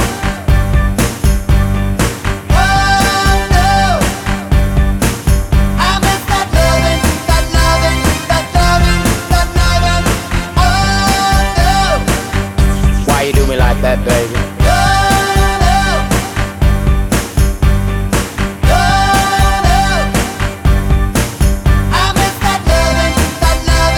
with rap R'n'B / Hip Hop 3:57 Buy £1.50